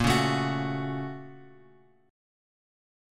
A# Minor Major 7th Sharp 5th